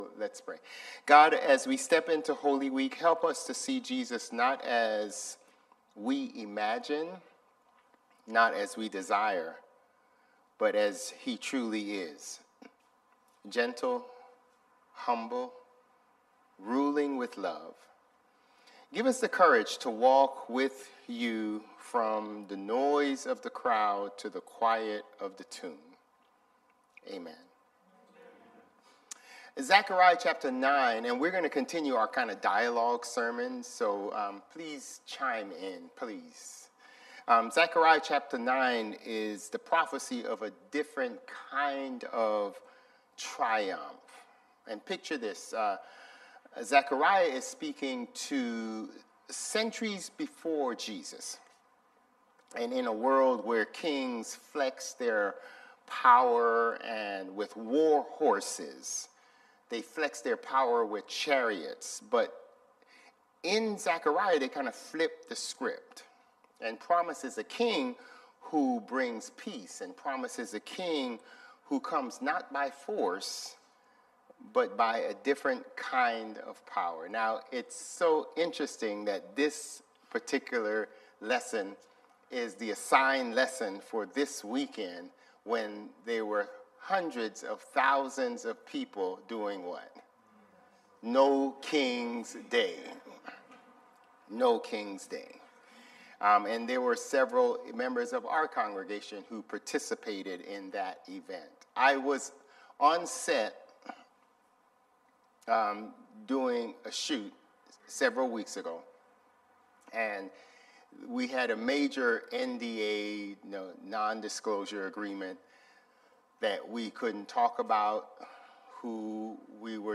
Sermons | Bethel Lutheran Church
March 29 Worship